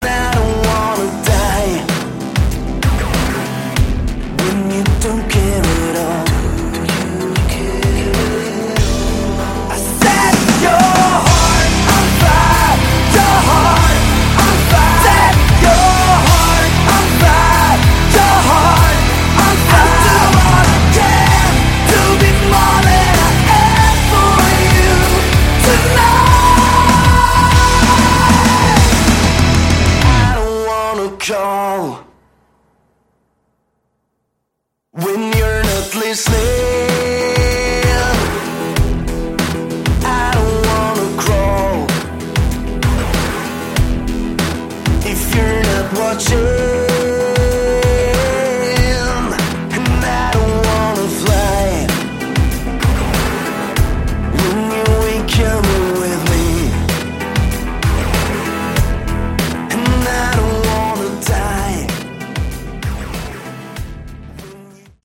Category: Modern Synth Hard Rock